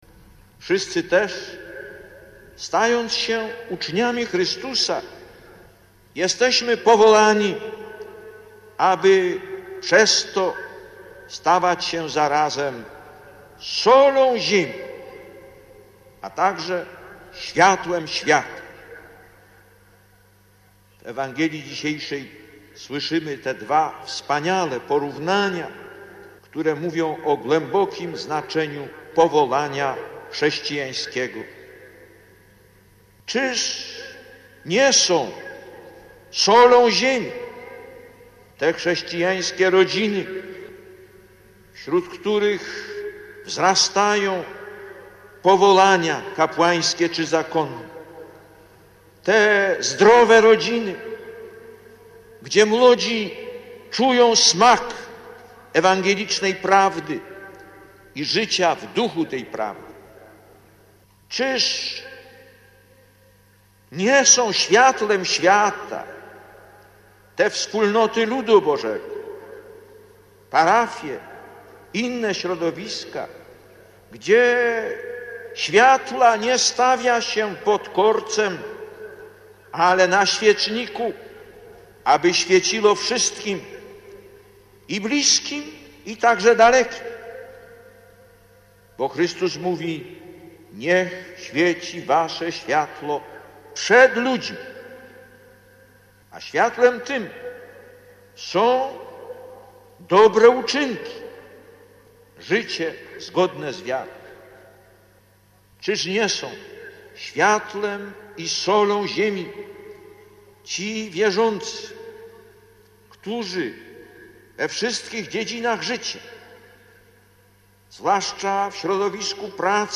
Lektor: Homilia w czasie Mszy św. połączonej z udzieleniem święceń kapłańskich (Lublin, 9 czerwca 1987 -